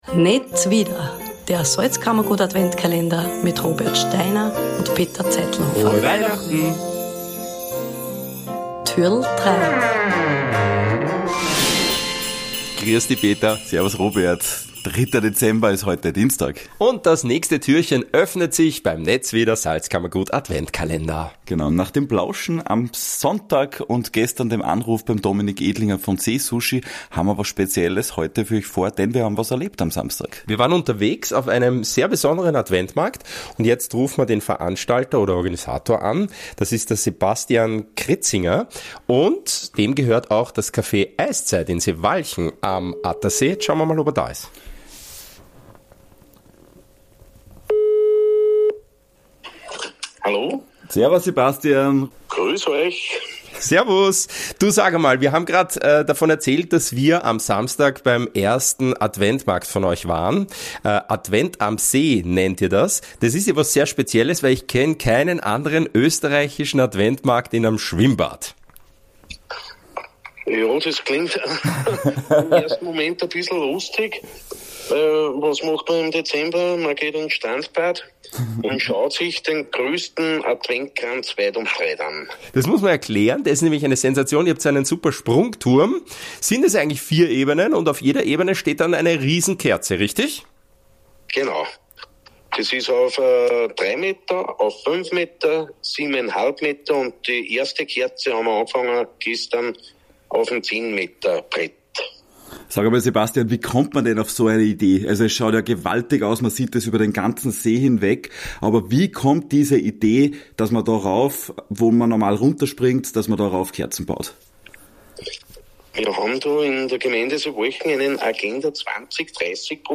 Ein Anruf